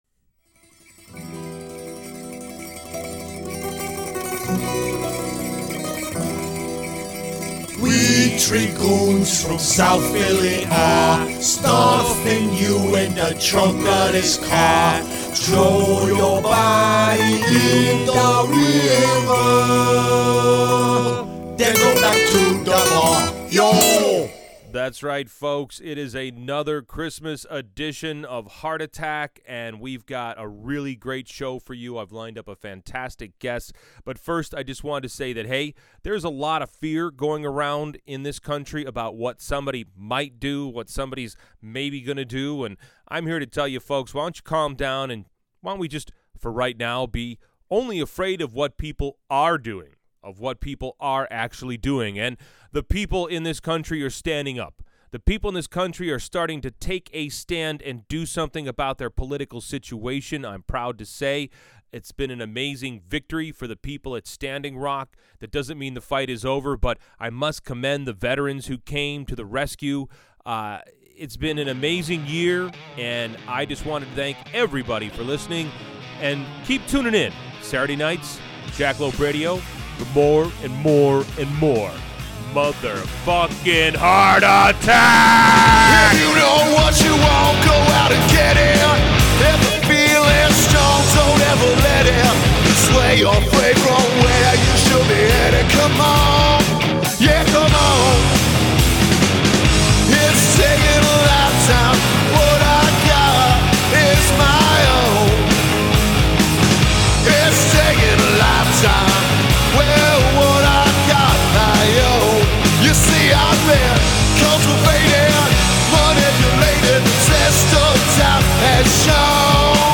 This was even more of a meandering discussion then last time. We started with the election hacking scandal and branched out to cover all manner of terrifying technology.